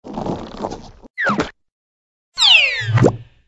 AV_teleport.ogg